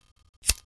x1_battle_nilu_attack.wav